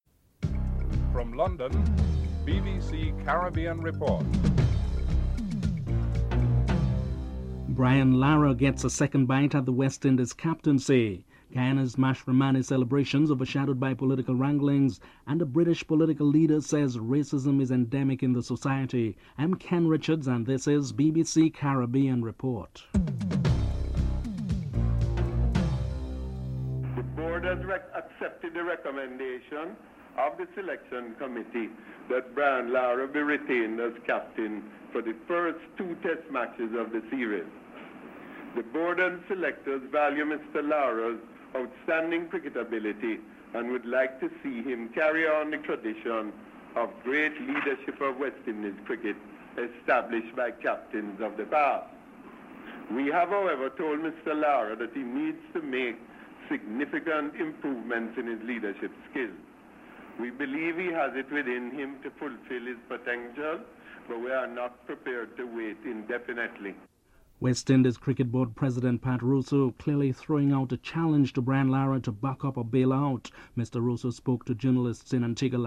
1. Headlines with anchor
3. The current impasse over the Caribbean brokered peace talks has overshadowed public events during a Guyana public holiday. Guyana’s President Janet Jagan pleads for unity and opposition leader Desmond Hoyte comments on reasons behind his party’s non-participation in the event.